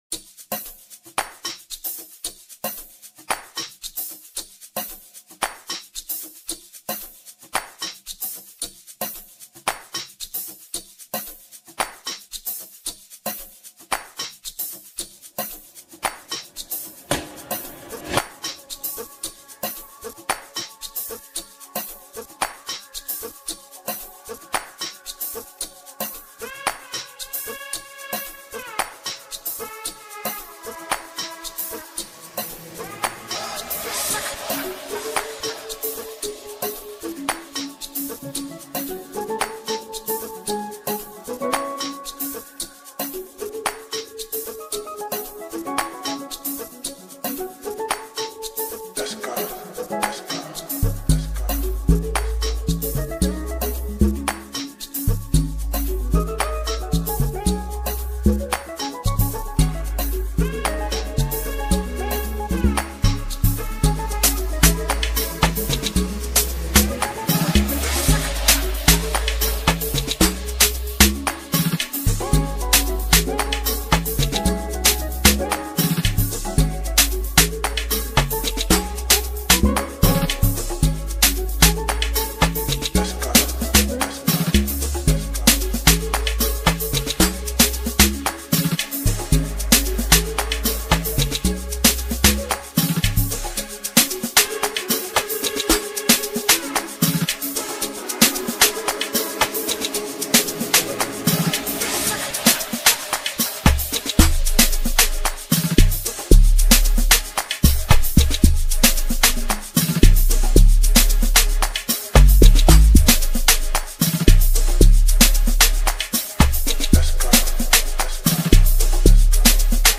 Foreign MusicSouth African